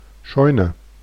Ääntäminen
Ääntäminen France: IPA: /kjɔsk/ Haettu sana löytyi näillä lähdekielillä: ranska Käännös Ääninäyte Substantiivit 1.